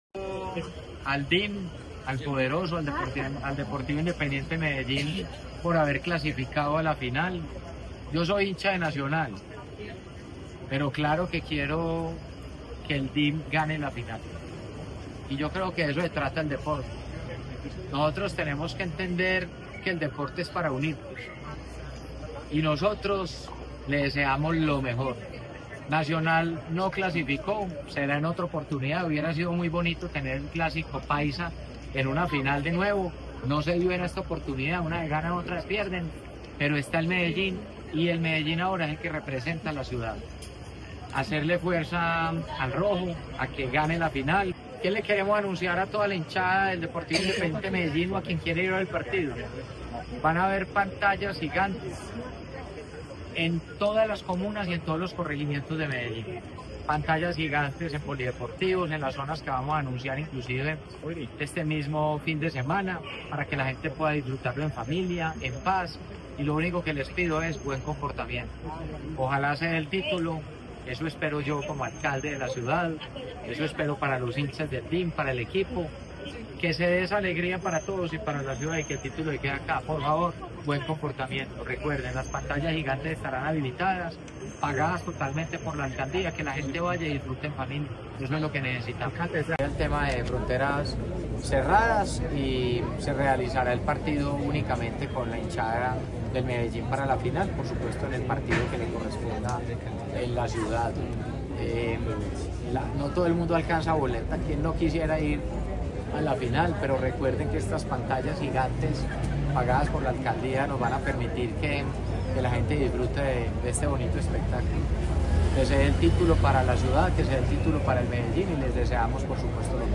Declaraciones alcalde de Medellín, Federico Gutiérrez
Declaraciones-alcalde-de-Medellin-Federico-Gutierrez-7.mp3